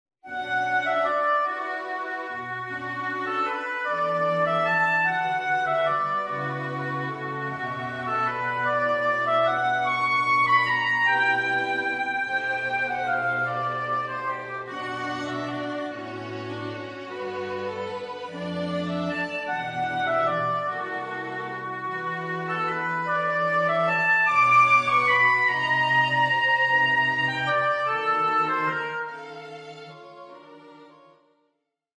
opening of II Siciliano:
A charming English neoclassical work in three movements:
II Tempo di Siciliana